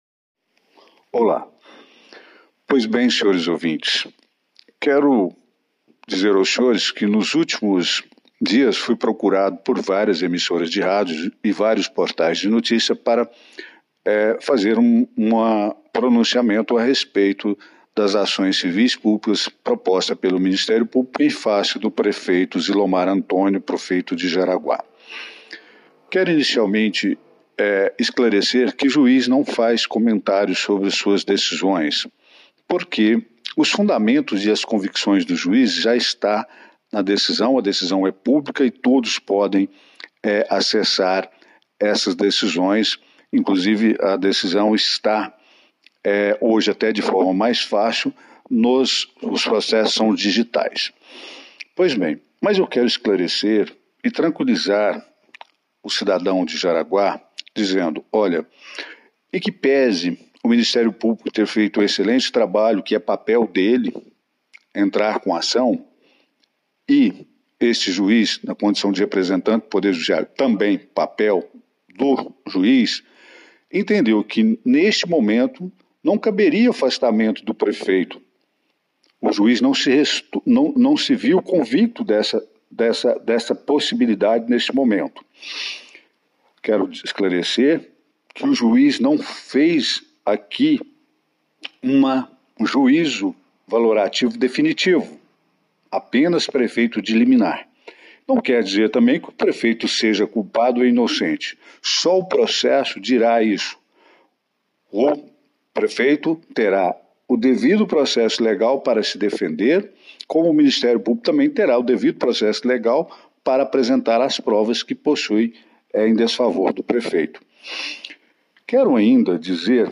Em resposta, o juiz, em um áudio postado na íntegra no fim do texto, disse que o Ministério Público tem a atribuição de oferecer denúncias, mas cabe ao judiciário julgar, com base na análise dos fatos.